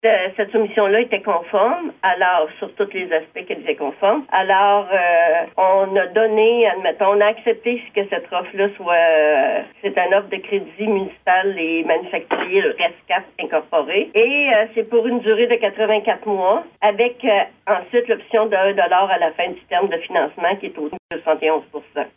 La Municipalité n’a reçu qu’une seule soumission de la part d’Aréo-Feu, une entreprise de Longueuil, pour un montant de 973 381,80 $, comprenant les taxes applicables. La mairesse de Grand-Remous, Jocelyne Lyrette, explique que cette soumission a été acceptée par le conseil municipal :